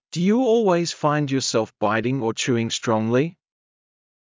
ﾄﾞｩ ﾕｳ ｵｰﾙｳｪｲｽﾞ ﾌｧｲﾝﾄﾞ ﾕｱｾﾙﾌ ﾊﾞｲﾃｨﾝｸﾞ ｵｱ ﾁｭｰｲﾝｸﾞ ｽﾄﾛﾝｸﾞﾘｰ